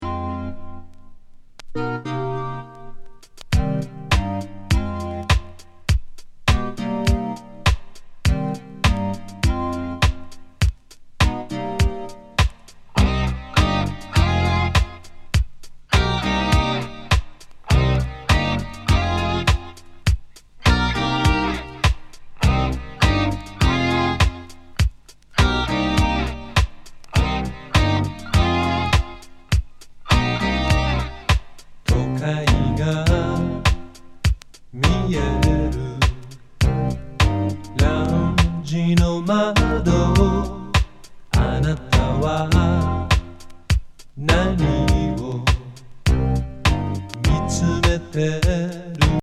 完璧バレアリック